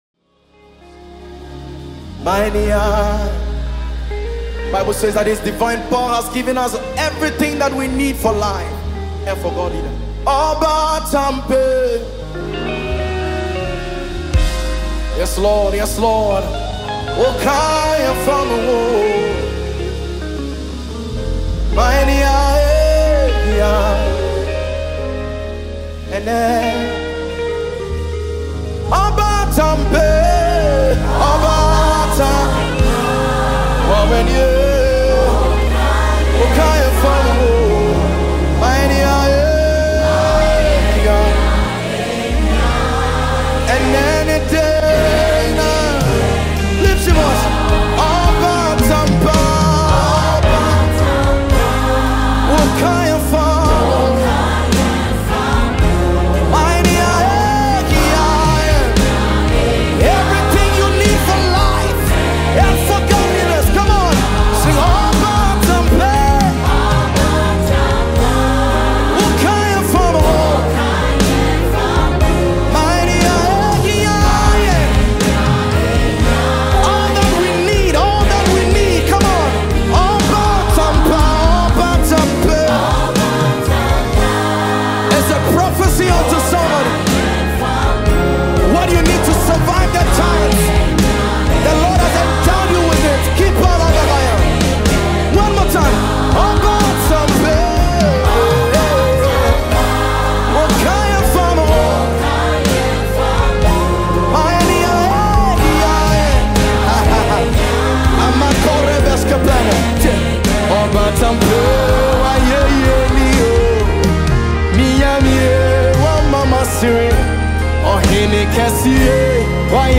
March 26, 2025 Publisher 01 Gospel 0